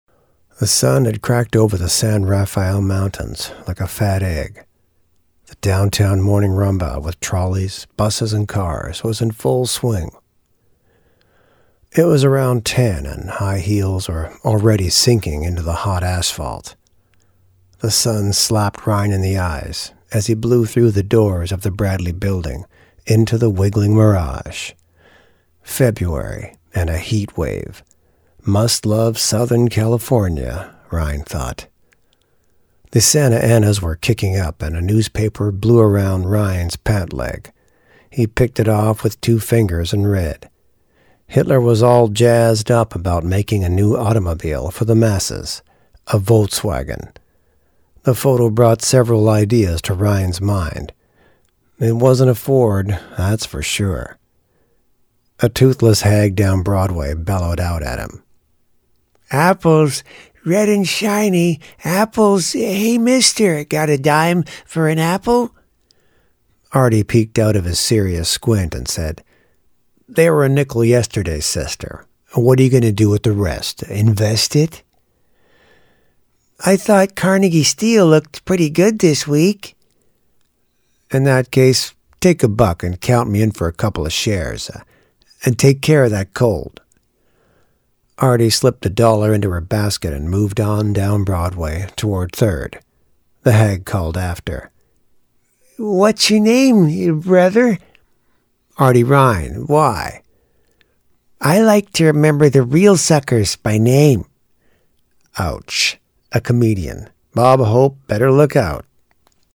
Audiobook Crime Drama
Audiobook_Crime_Drama.mp3